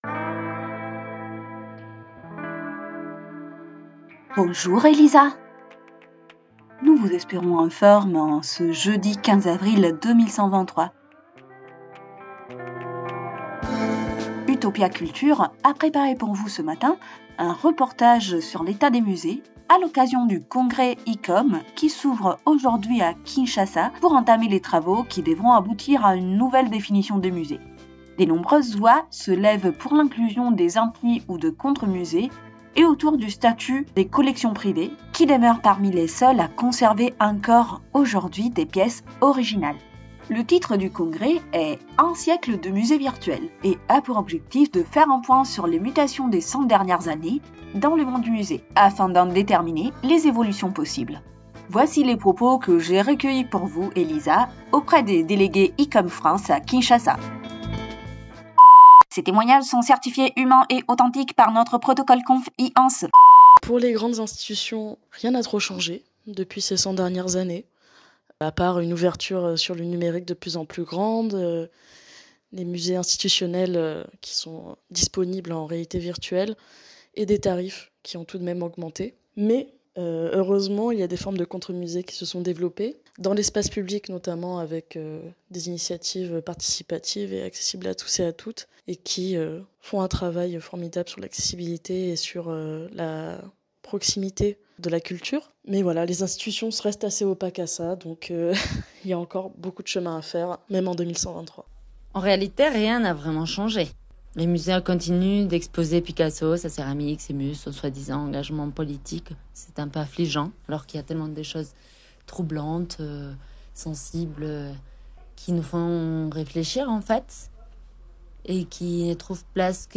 Pour réaliser cette émission, nous avons demandé à des artistes, à des artisans et à des étudiant.e.s de l’Ecole du Louvre de nous parler du musée du futur.
Les témoignages reposent tantôt sur des préoccupations, tantôt sur des idéaux.